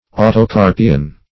Search Result for " autocarpian" : The Collaborative International Dictionary of English v.0.48: Autocarpous \Au`to*car"pous\, Autocarpian \Au`to*car"pi*an\, a. [Auto- + Gr. karpo`s fruit.]